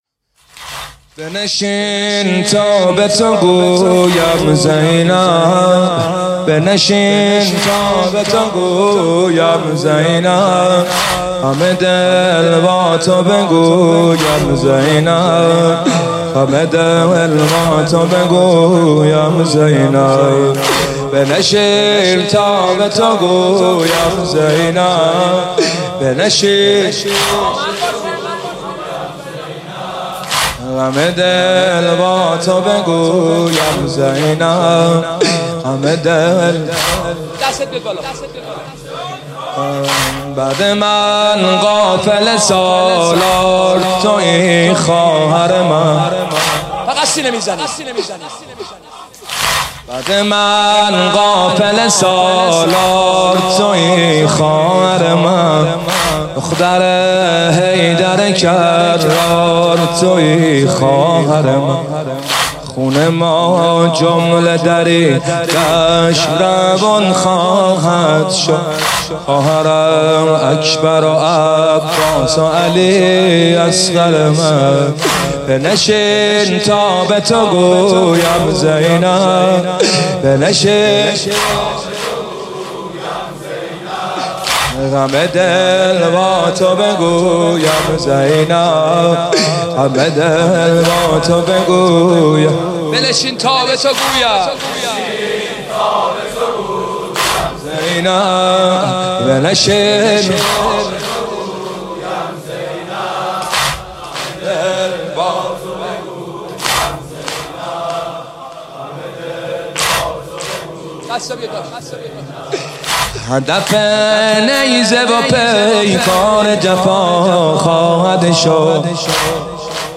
شب چهارم محرم98 هیات روضه العباس (ع) تهران